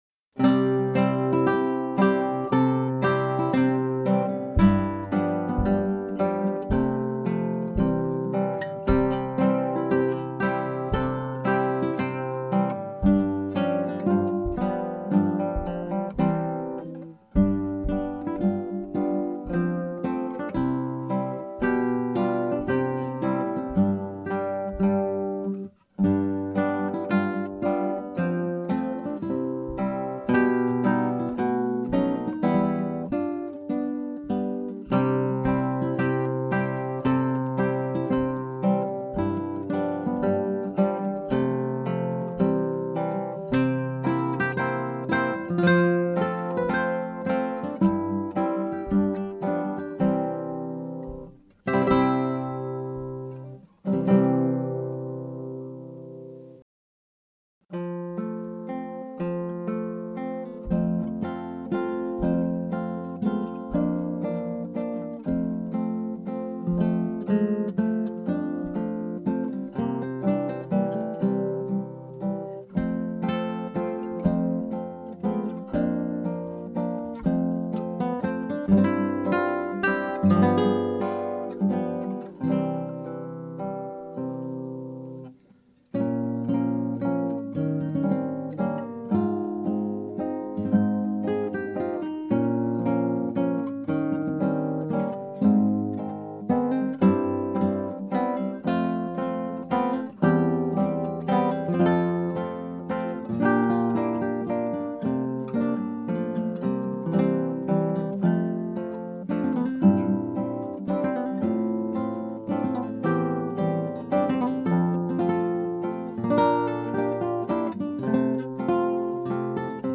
Scraps from the Operas arranged for Two Guitars
Scrap 1: Tempo di Marcia.
Scrap 2 (0:58): Andantino.
Scrap 3 (2:11): Marziale.
The closing measures in triplets actually form introductory material for the chorus when it is first heard in Act 2.